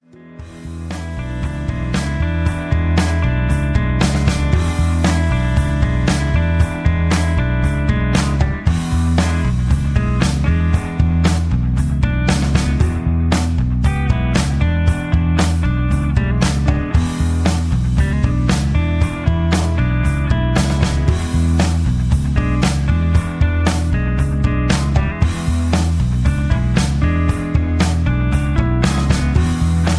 rock and roll, country rock